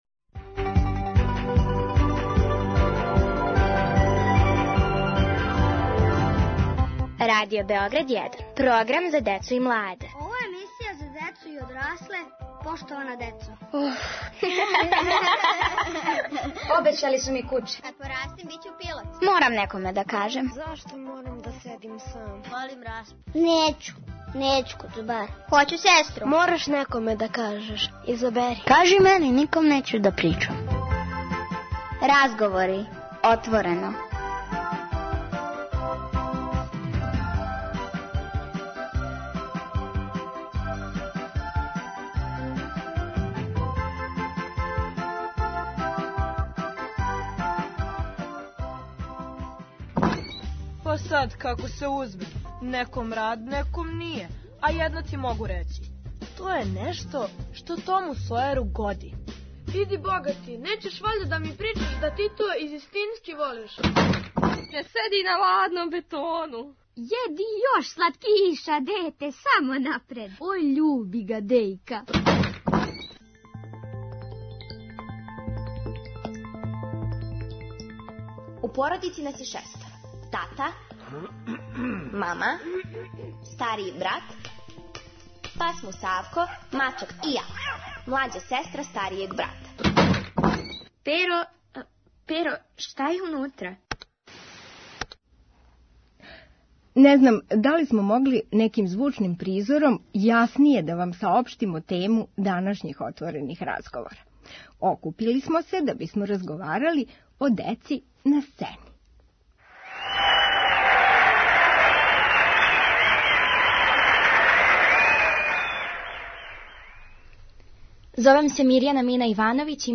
Са својим сценским искуствима упознаће нас чланови Дечије драмске групе Радио Београда и извођачи опере за децу Дечија соба, која је уврштена у програм овогодишњег БЕМУС-а.